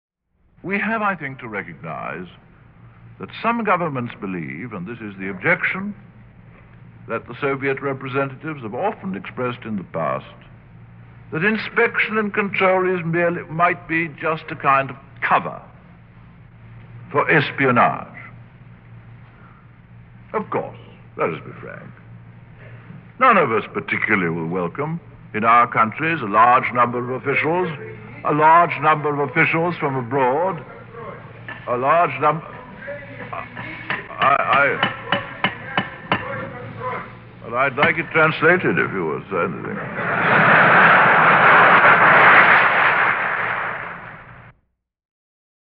McMillan interrupted by Khruschev